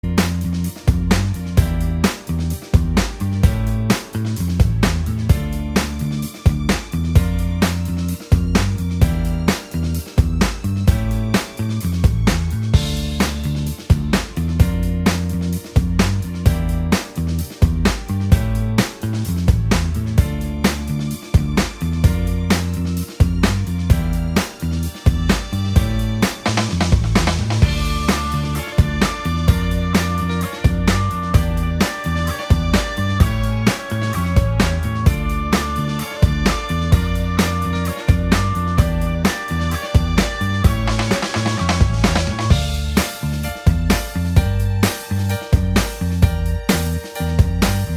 Minus All Guitars R'n'B / Hip Hop 3:38 Buy £1.50